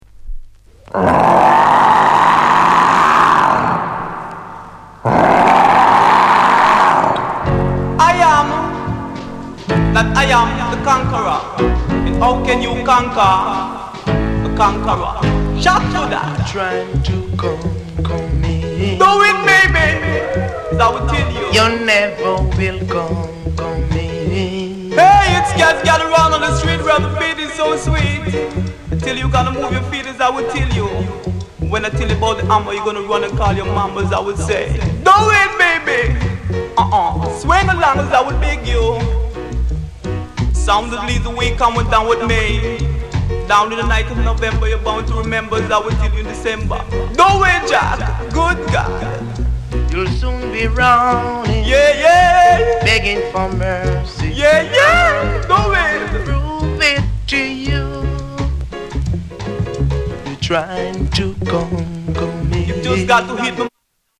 SHUFFLE INST